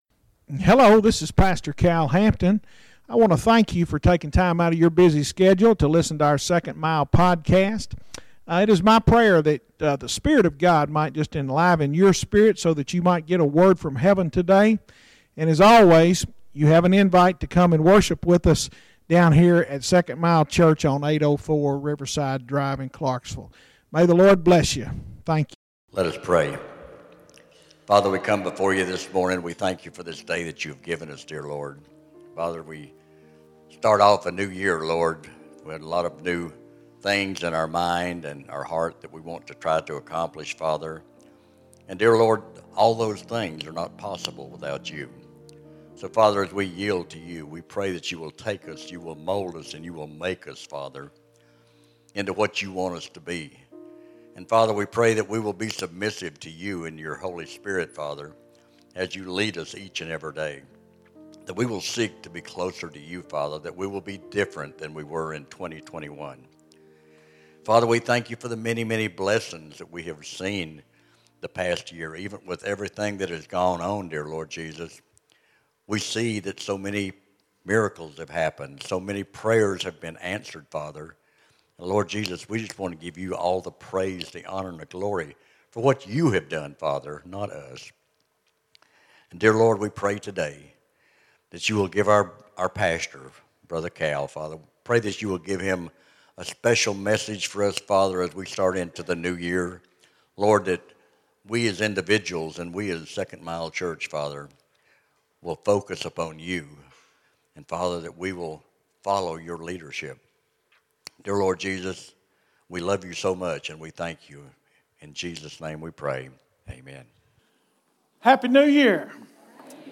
Sermons Archive - Page 118 of 311 - 2nd Mile Church